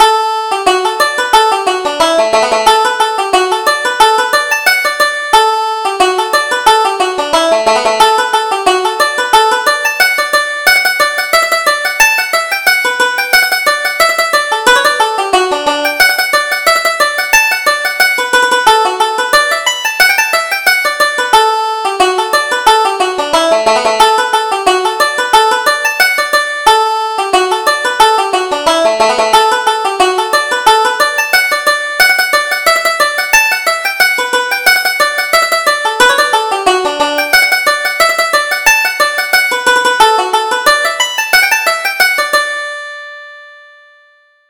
Reel: The Maid of Athlone